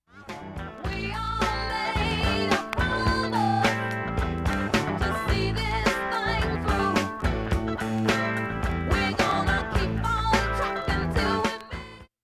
曲間などもチリも無く
音質良好全曲試聴済み。
A-1序盤にかすかなプツが３回出ます。
骨太で肉厚なファンク／ソウル・アルバム